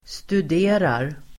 Uttal: [stud'e:rar]